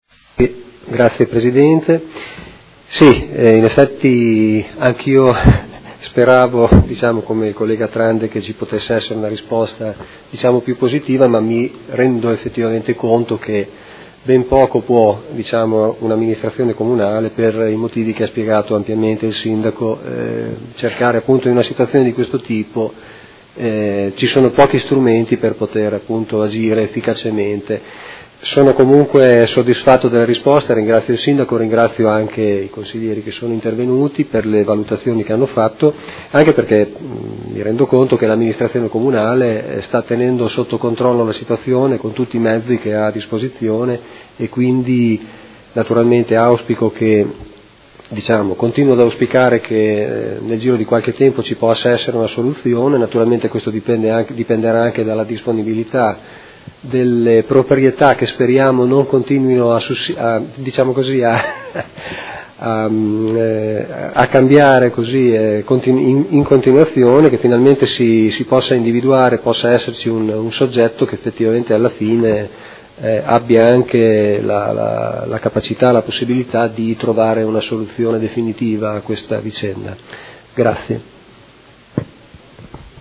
Seduta del 31/03/2016. Interrogazione dei Consiglieri Malferrari e Trande (P.D.) avente per oggetto: Degrado struttura ex albergo Holiday Inn. Replica